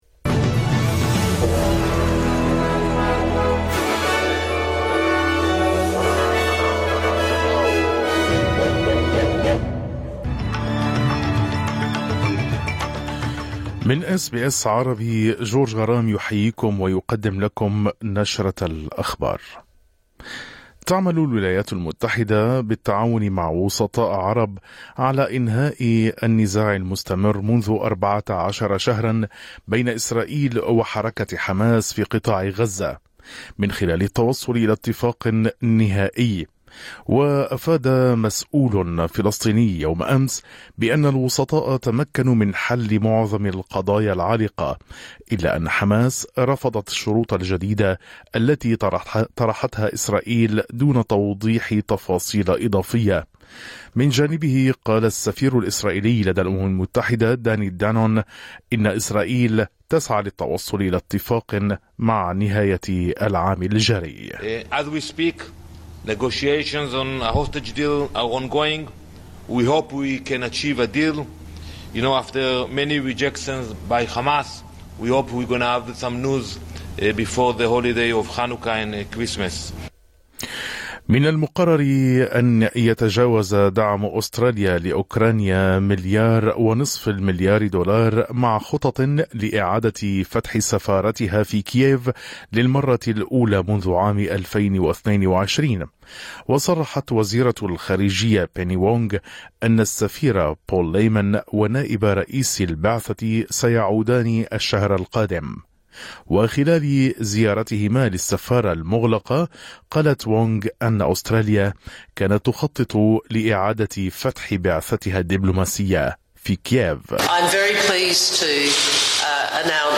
نشرة أخبار الظهيرة 20/12/2024